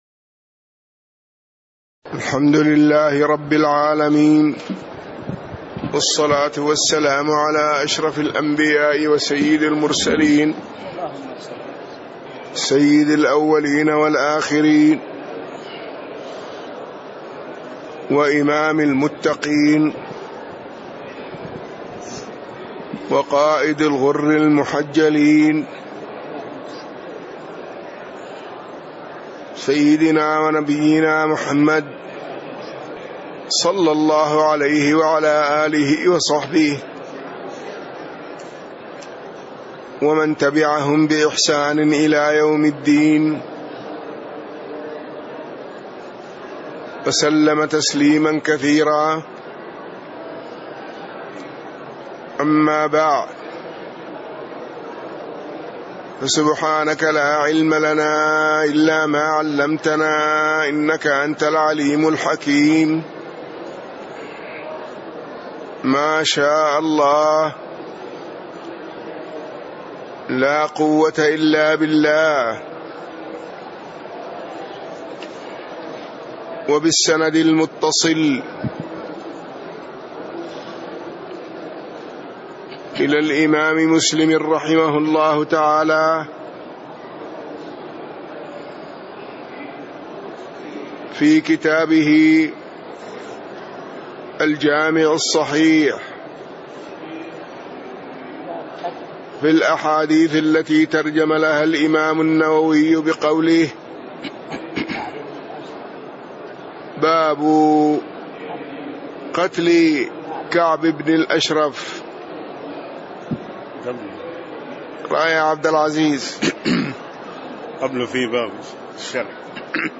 تاريخ النشر ١٧ محرم ١٤٣٦ هـ المكان: المسجد النبوي الشيخ